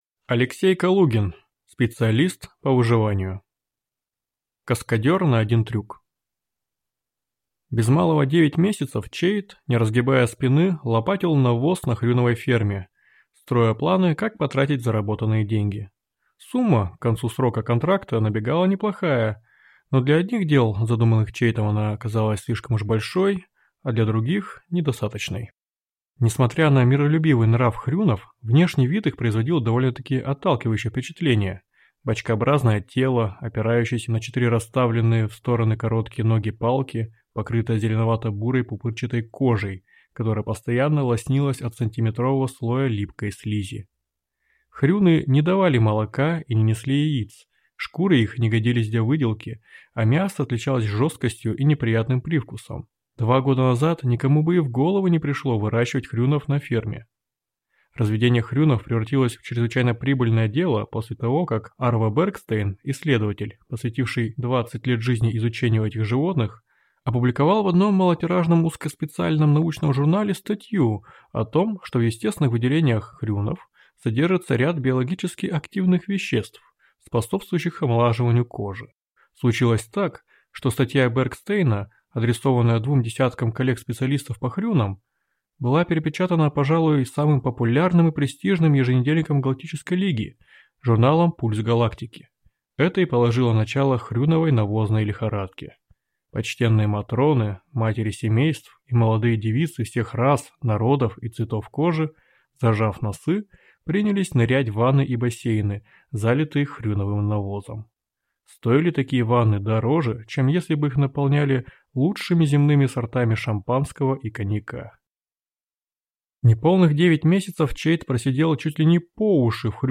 Аудиокнига Специалист по выживанию (сборник) | Библиотека аудиокниг